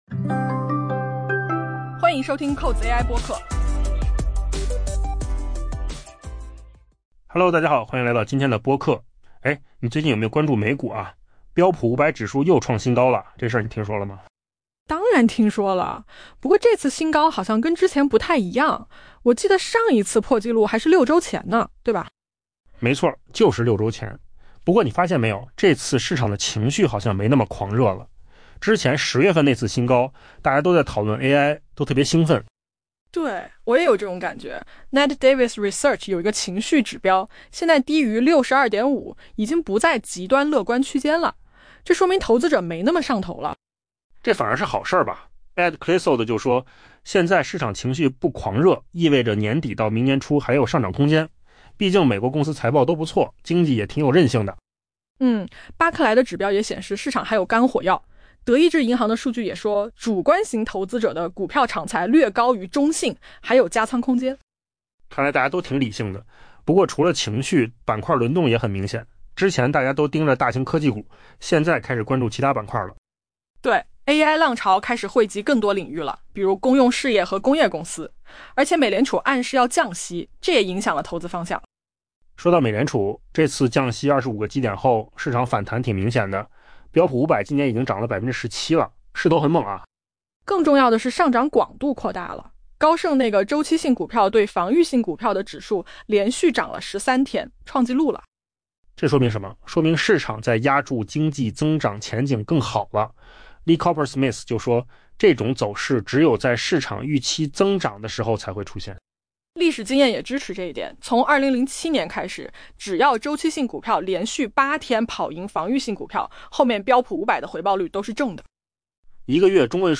AI 播客：换个方式听新闻 下载 mp3 音频由扣子空间生成 标普 500 指数周四再次收于历史新高，这在 2025 年虽不罕见，但这一次距离上一次破纪录已有六周。